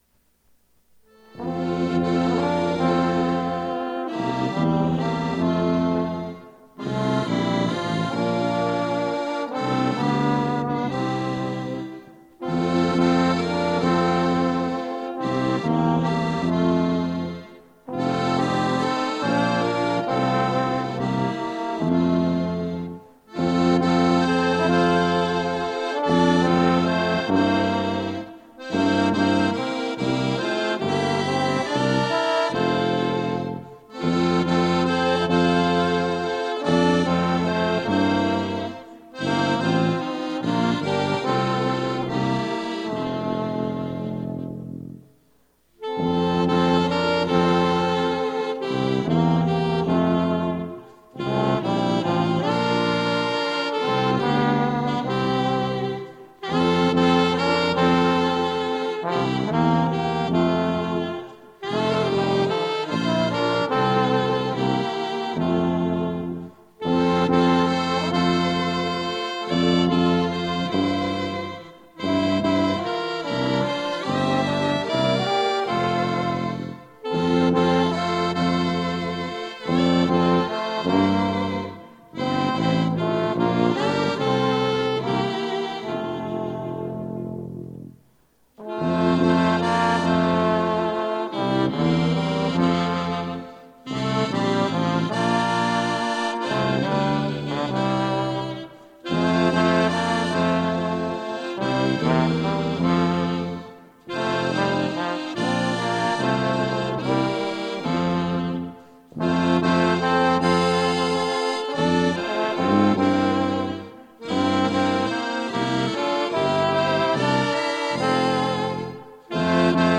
acordeon
sax-alto
trombone
tuba